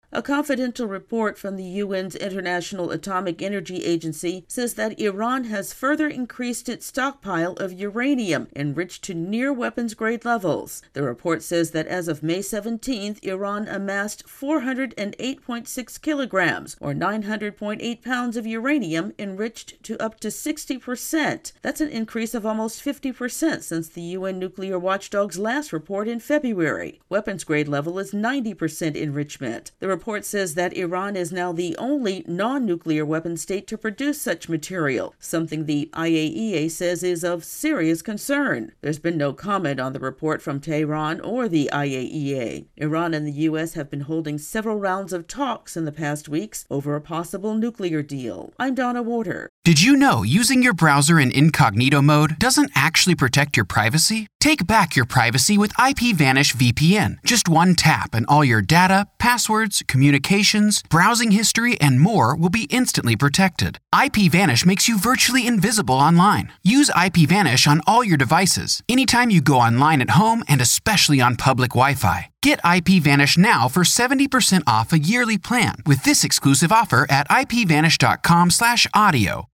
As the U.S. and Tehran hold nuclear talks, there's a confidential report from the U.N.'s nuclear watch dog that it says is of "serious concern." AP correspondent